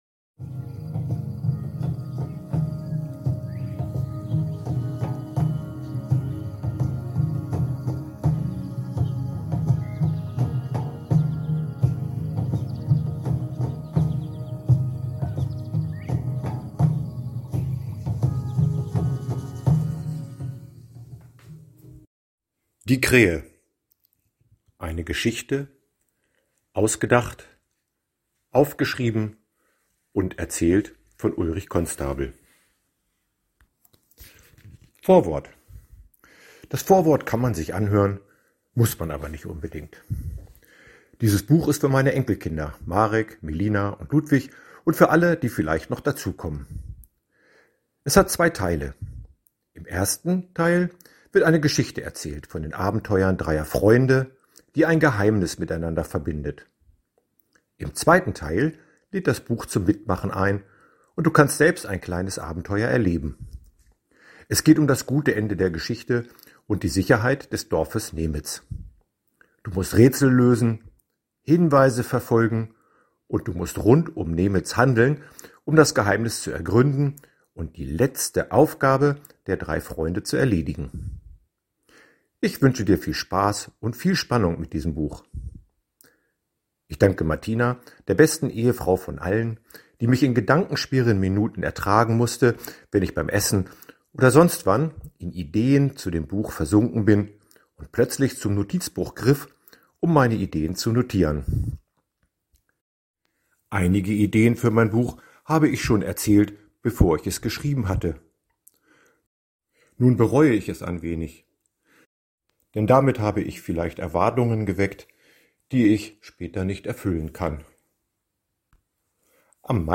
mp3-Hörbuch
Die Krähe_Hörbuch_1.mp3